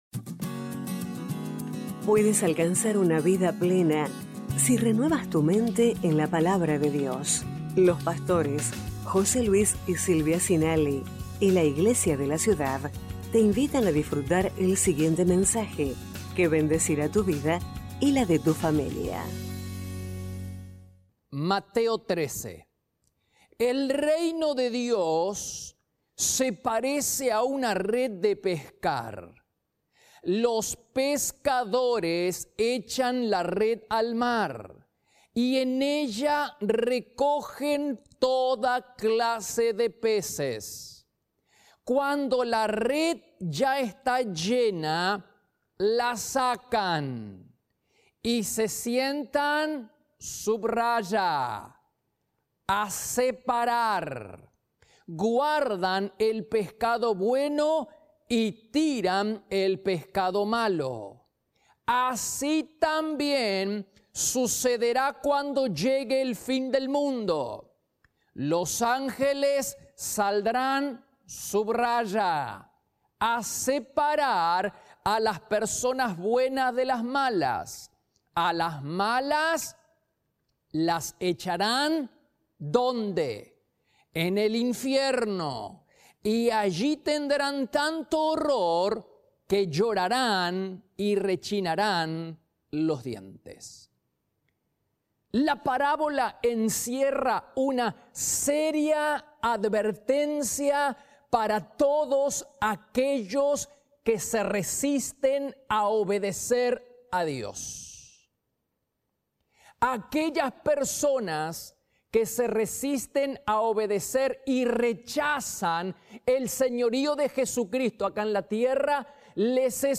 Iglesia de la Ciudad - Mensajes / Mira a Cristo, ¡hay vida en esa mirada! 23/05/21 #1145